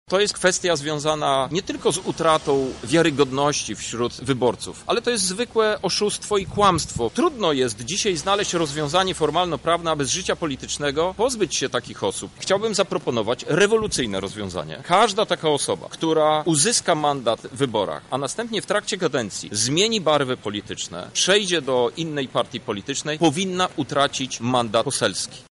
Trudno zrozumieć, że jednego dni polityk idzie w czarnym marszu, a drugiego stoi w kościele pod amboną składając ręce karnie do modlitwy – mówi Krzysztof Hetman: